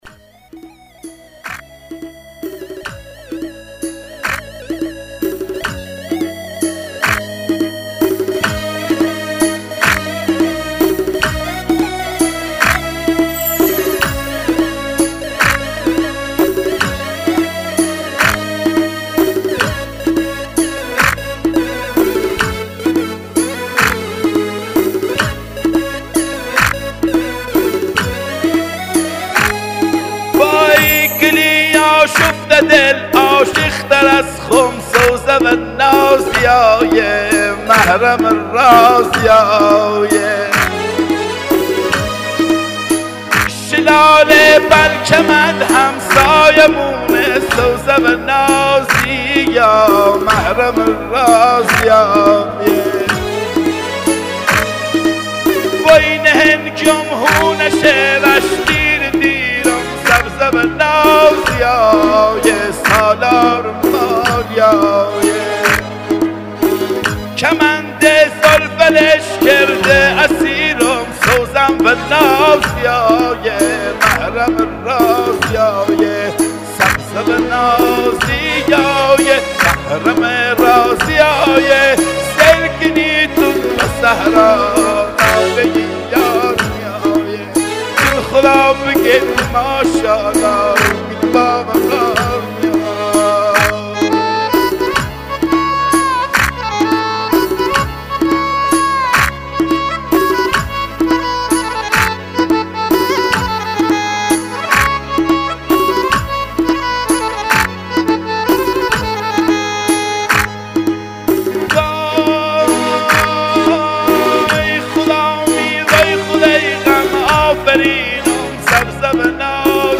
عاشقانه بی تکرار ❤‍🔥💔
آهنگ لری
موسیقی اصیل لری _ بختیاری